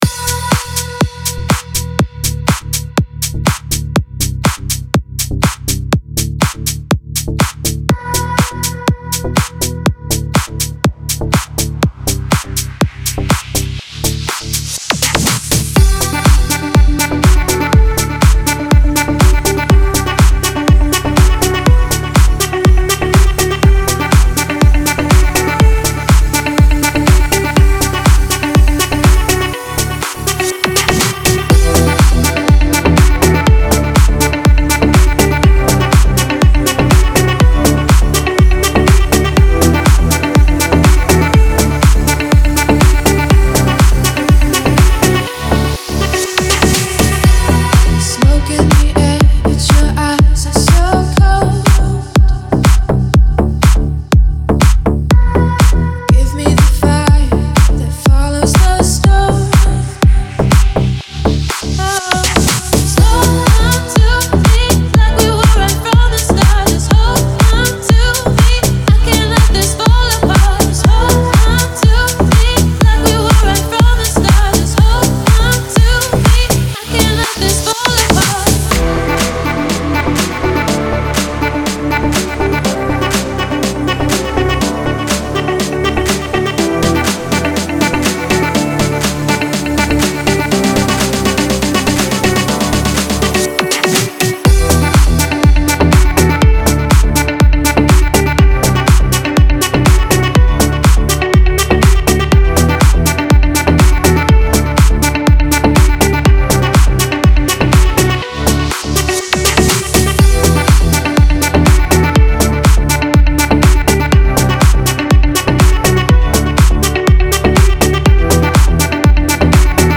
Ритмичная музыка для спорта
Музыка для тренировок